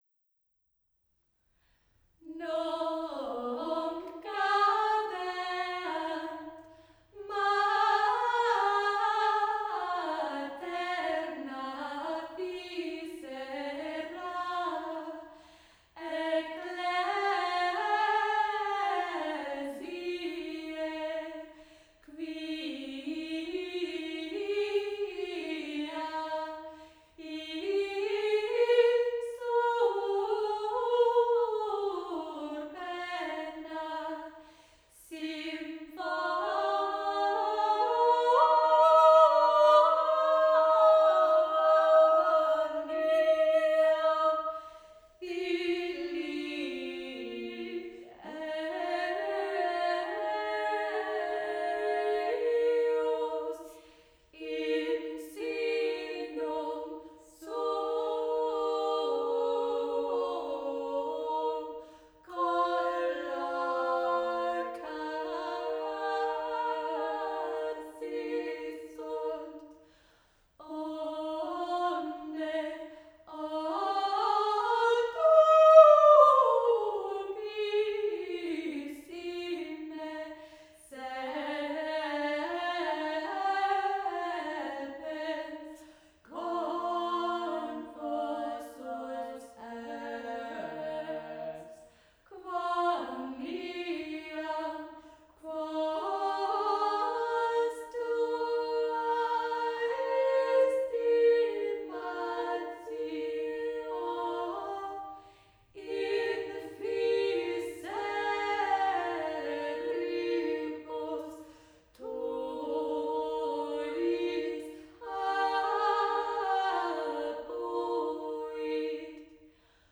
Here VoiCE sing the Hildegard piece and the Georgian healing song already included in the other files. But this time they are arranged round the microphone, as in the photo.
Ambisonic
Ambisonic order: F (4 ch) 1st order 3D
Microphone name: Core Sound TetraMic
Array type: tetrahedral soundfield
Capsule type: cardioid